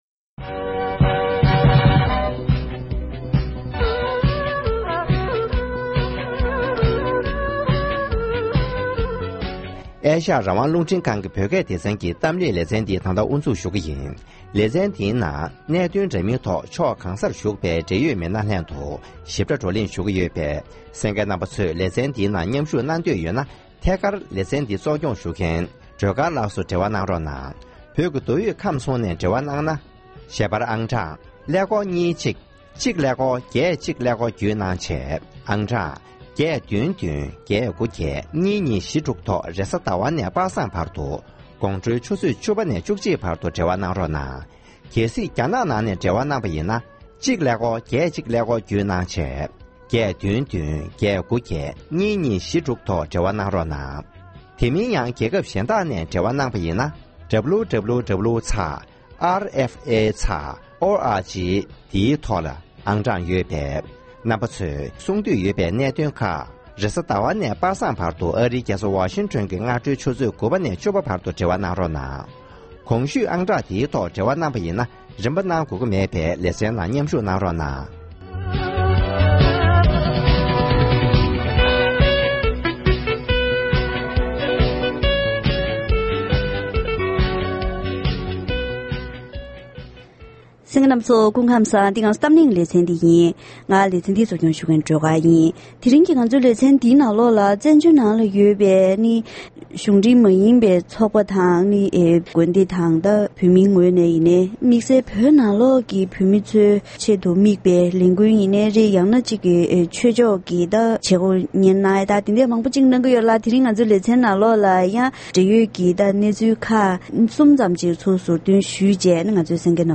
བོད་ཡུལ་བདེ་བའི་སྨོན་ལམ་དང་གོམ་བགྲོད་སྐོར་ལ་བགྲོ་གླེང་གནང་བ།